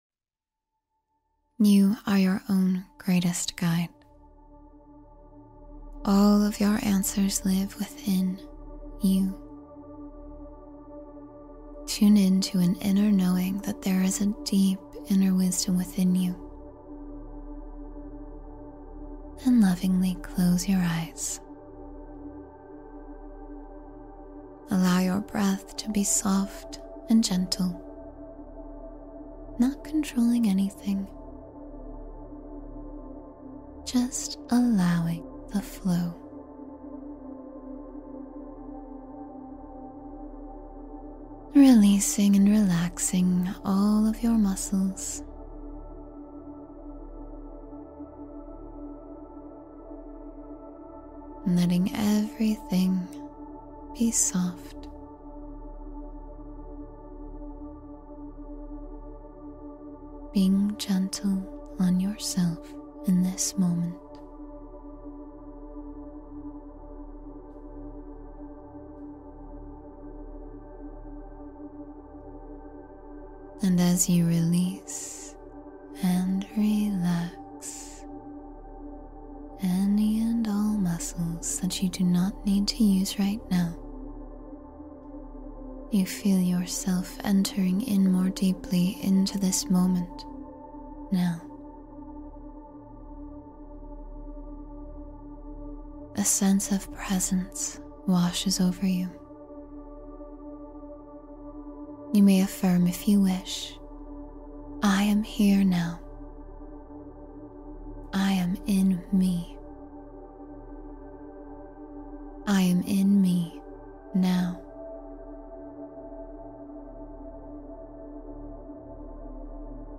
Bathe in the Golden Light of Renewal — Guided Meditation for Energy Cleansing